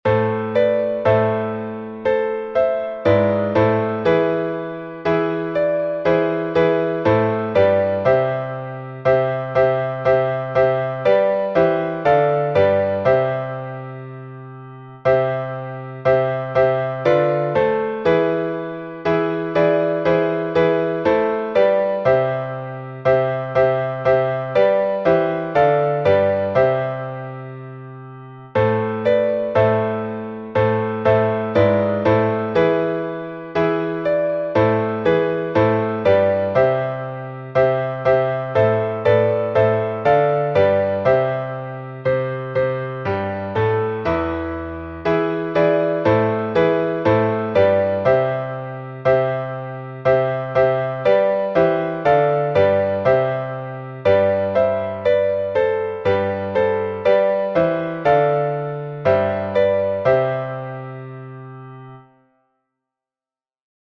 Глас 6